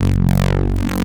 Desecrated bass hit 11.wav